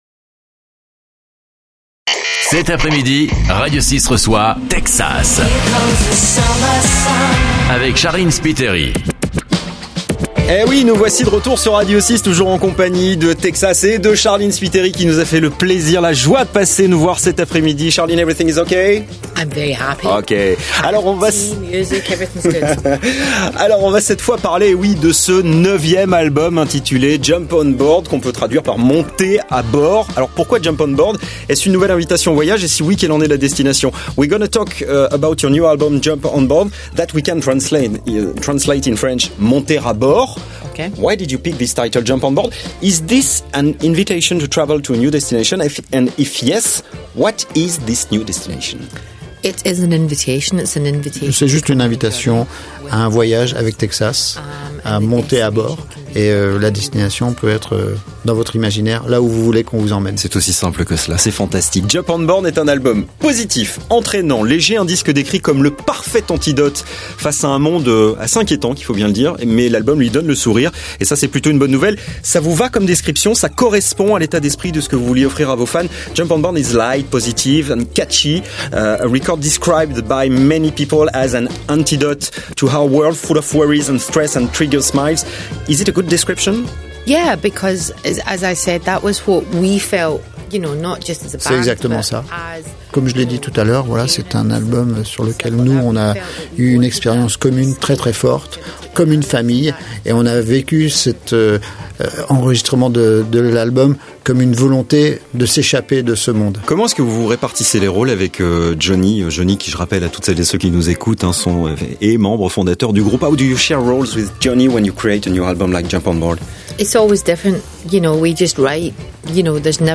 Le groupe Ecossais aux 38 millions d’albums vendus, était dans les studios de RADIO 6, jeudi 11 mai, pour enregistrer une interview exclusive à l'occasion de la sortie de leur nouvel album "Jump on board".